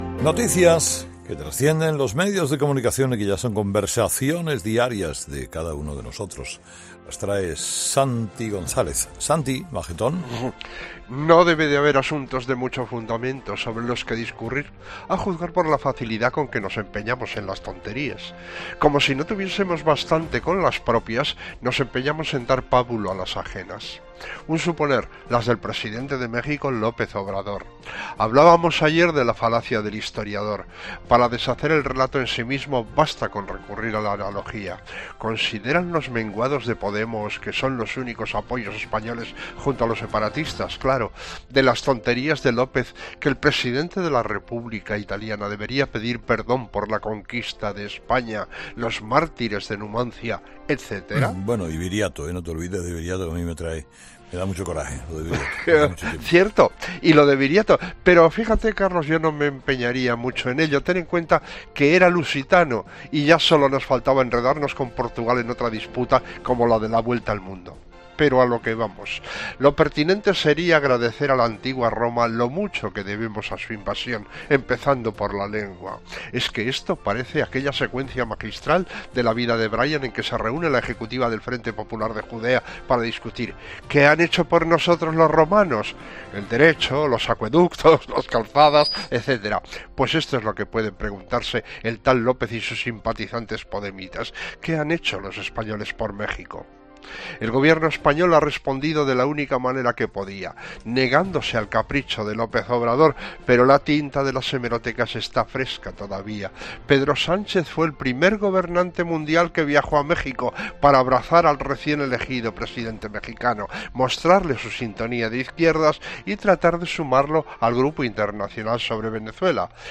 El comentario de Santi González en 'Herrera en COPE' del miércoles 27 de marzo de 2019